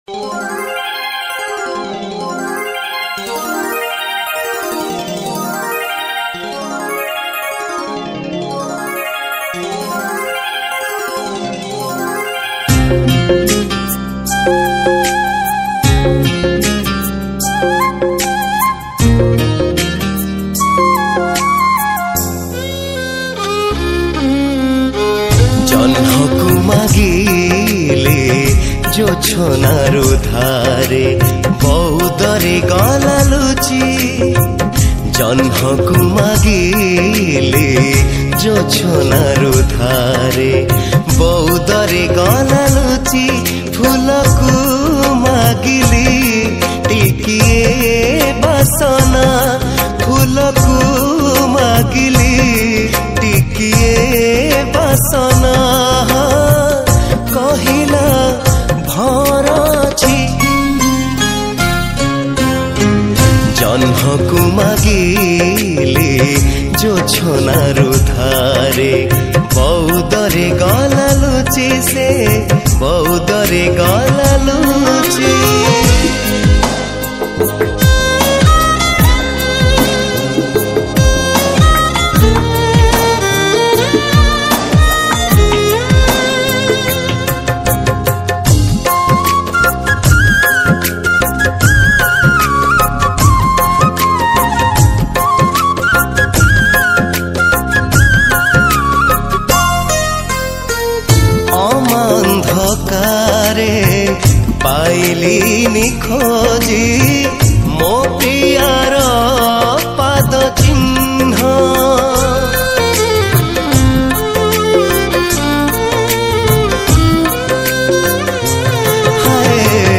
Romantic Odia Song